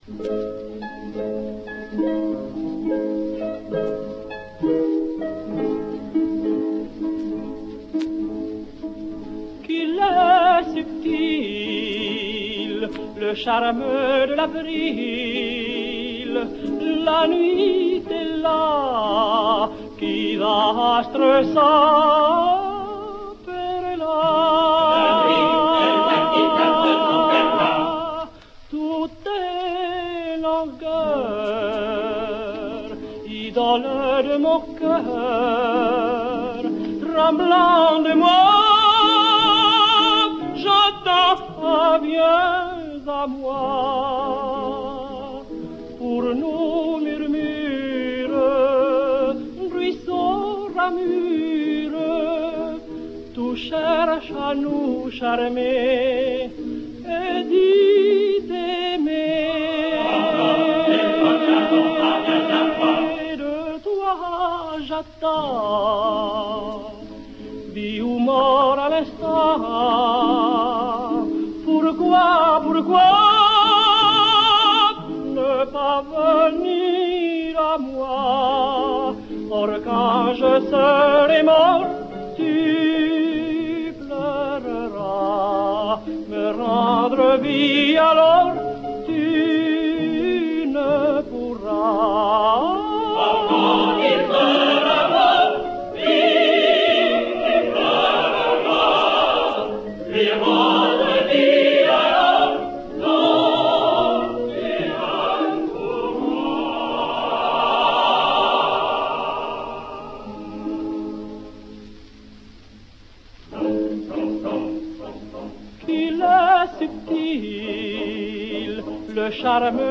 Luis Mariano sings Don Pasquale: